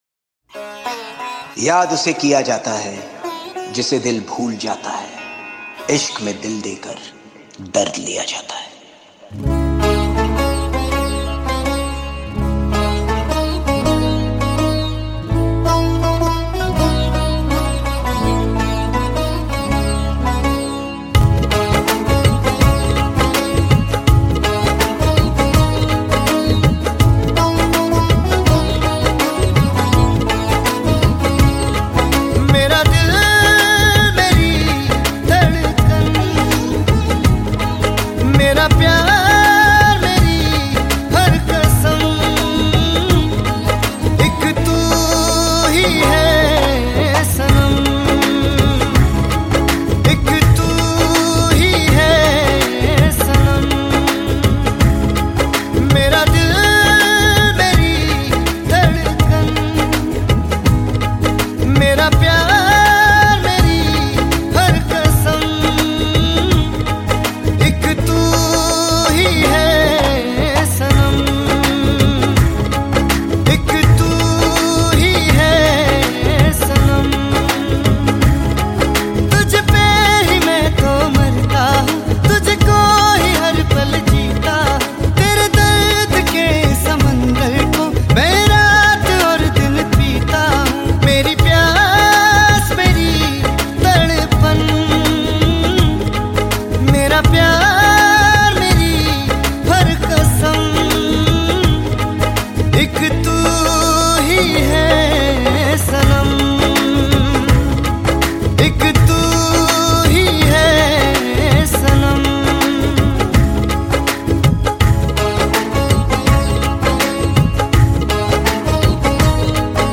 Hindi Pop Album Songs 2022